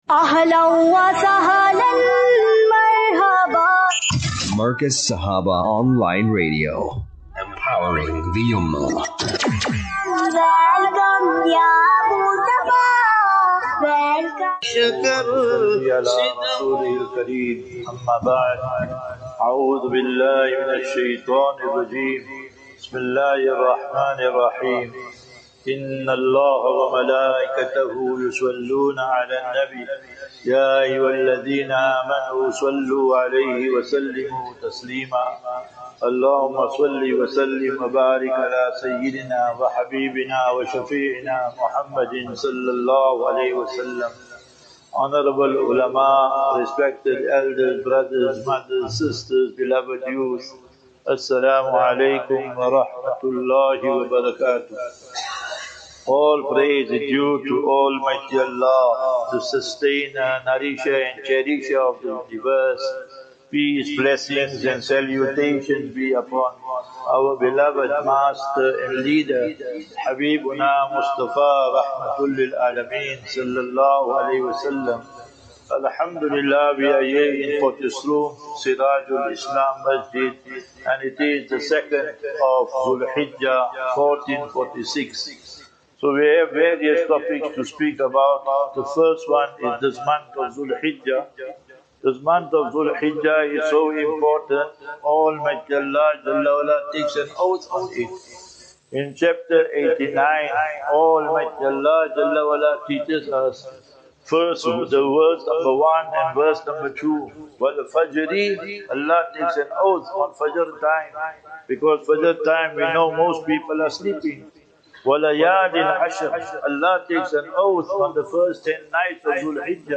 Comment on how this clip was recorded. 30 May 30 May 25 - Jumu,ah Lecture at Masjid Sirajul Islam (Potchestroom)